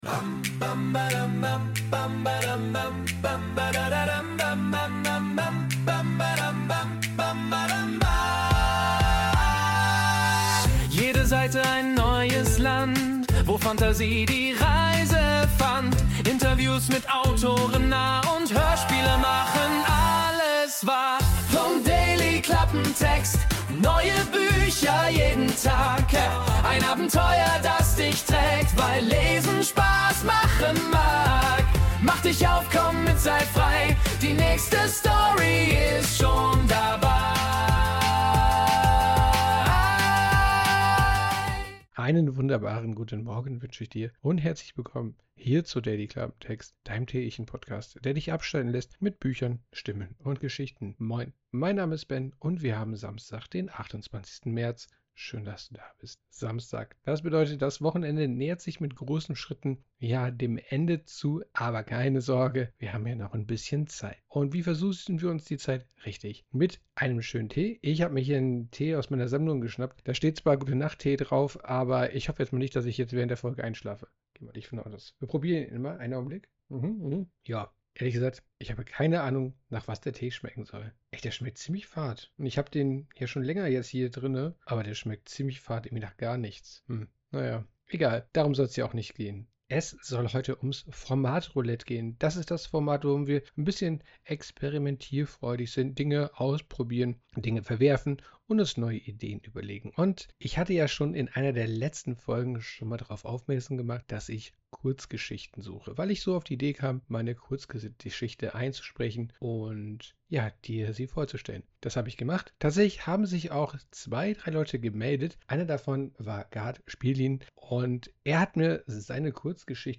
Diese Kurzgeschichte habe ich eingesprochen und mit einigen akustischen Elementen untermalt.